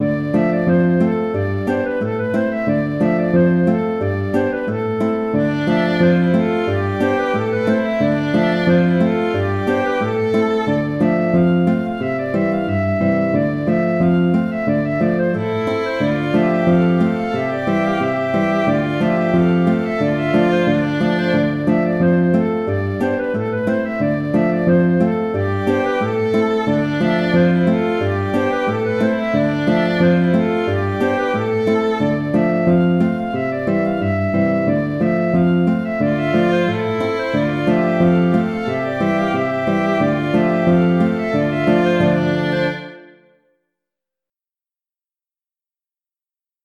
An dro de Saint-Nazaire (An dro) - Musique bretonne